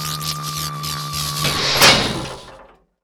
beampower.wav